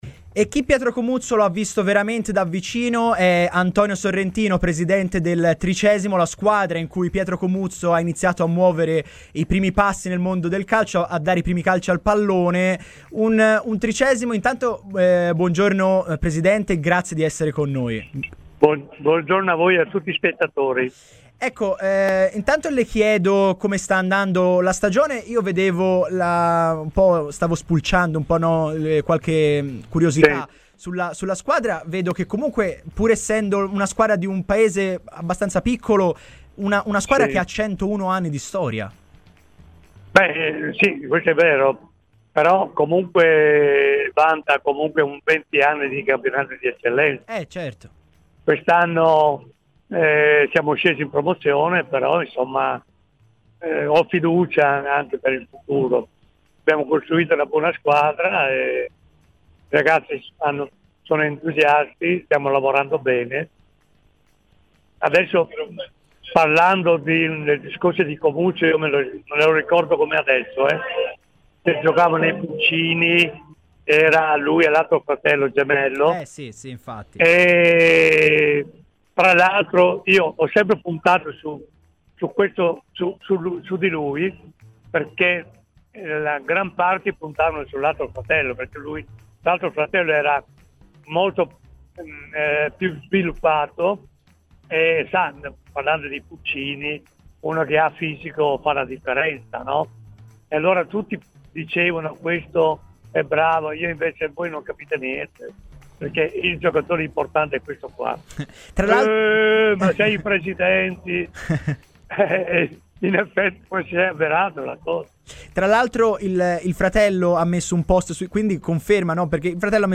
Il Presidente saluta commosso il collegamento: "Se avete occasione di vedere il ragazzo, ditegli che gli faccio tanti complimenti e con emozione gli dico auguri".